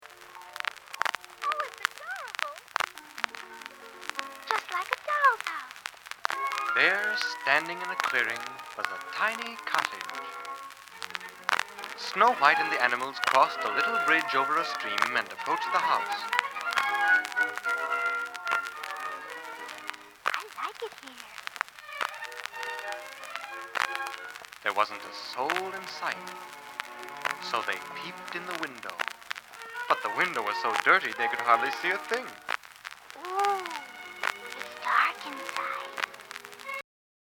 This recording is an early example of audio description, a strategy for making artwork and moving images accessible to blind people by converting images into verbal or textual form.